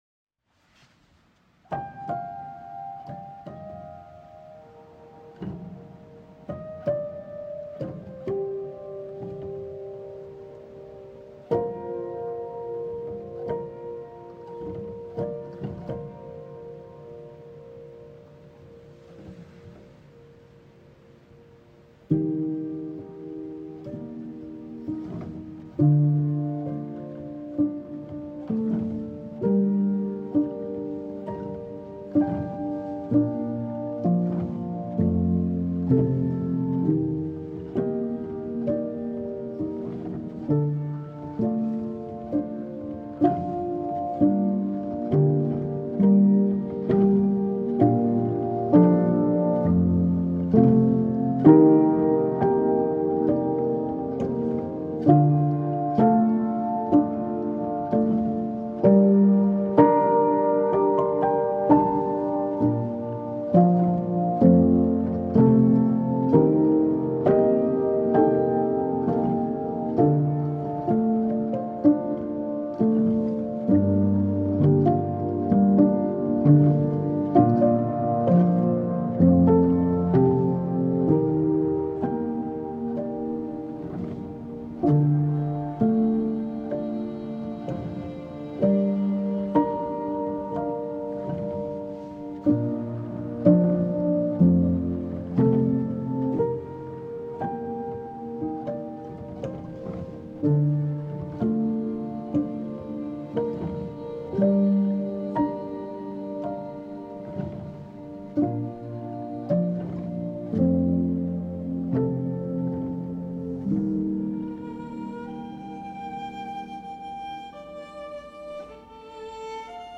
Трек размещён в разделе Зарубежная музыка / Классика.